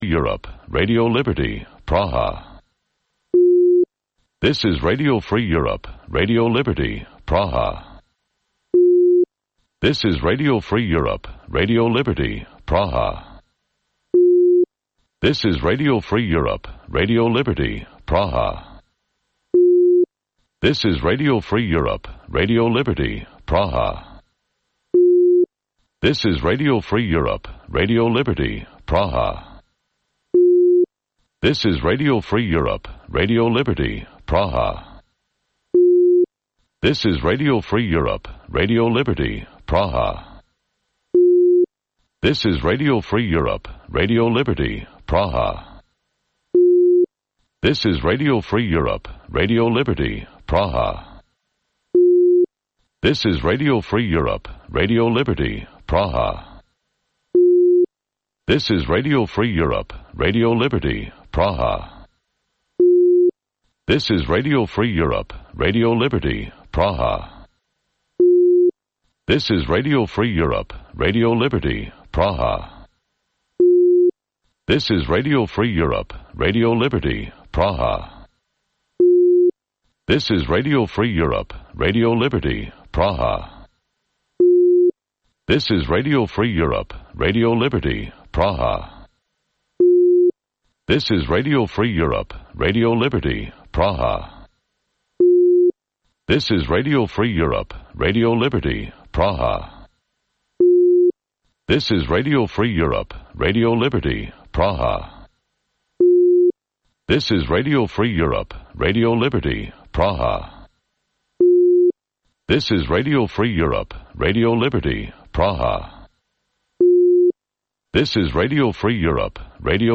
Вечірній ефір новин про події в Криму. Усе найважливіше, що сталося станом на цю годину.